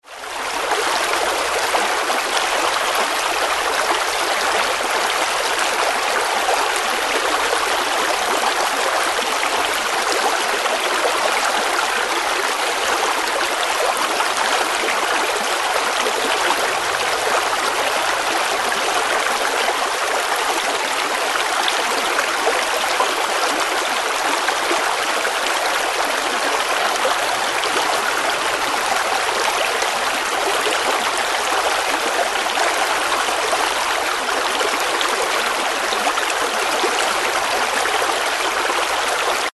Звуки реки